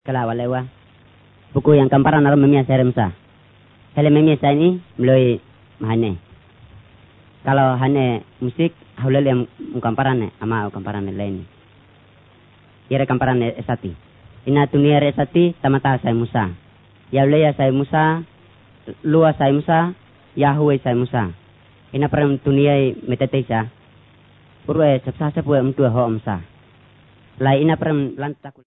These are recorded by mother-tongue speakers
Bible Overview, Bible Stories, Discipleship